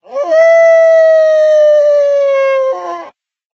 minecraft / sounds / mob / wolf / howl1.ogg
howl1.ogg